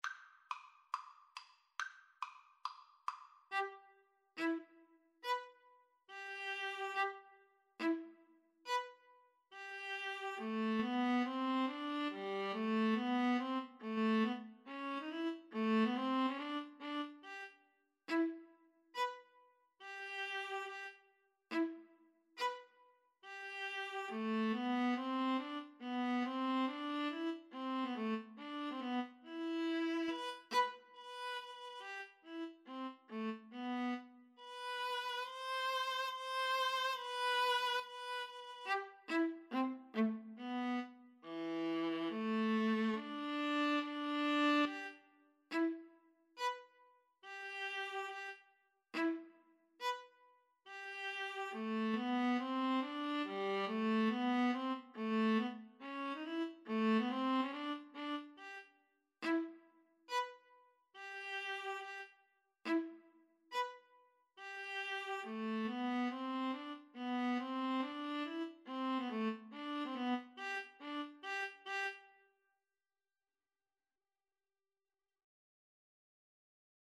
Tempo di marcia =140
Classical (View more Classical Violin-Viola Duet Music)